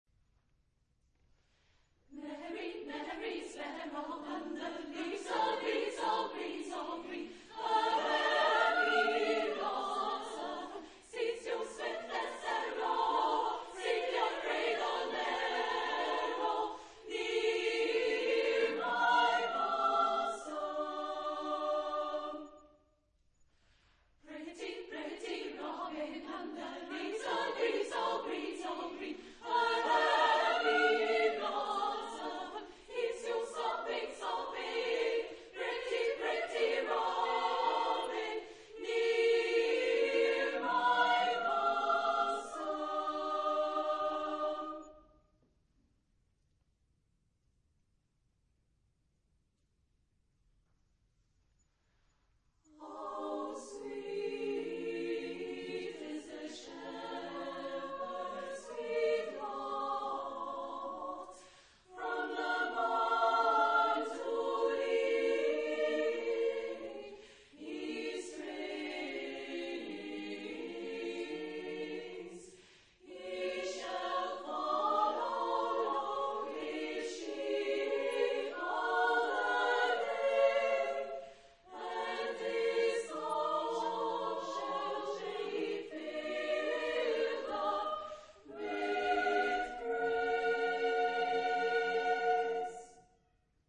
Genre-Style-Forme : Chanson ; Poème ; Folklore
Tonalité : sol majeur